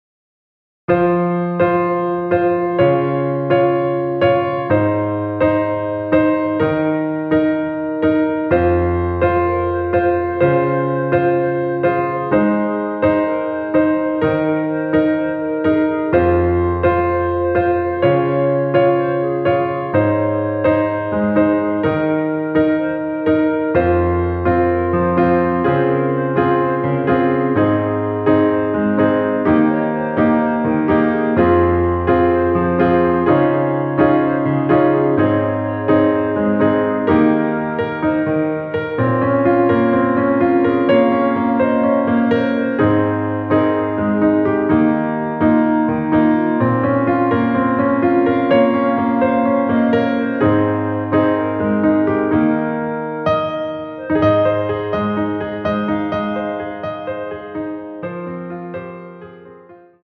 원키 멜로디 포함된 MR입니다.(미리듣기 확인)
Ab
앞부분30초, 뒷부분30초씩 편집해서 올려 드리고 있습니다.
중간에 음이 끈어지고 다시 나오는 이유는